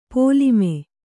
♪ pōlimē